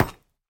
Minecraft Version Minecraft Version 1.21.5 Latest Release | Latest Snapshot 1.21.5 / assets / minecraft / sounds / block / netherite / step3.ogg Compare With Compare With Latest Release | Latest Snapshot
step3.ogg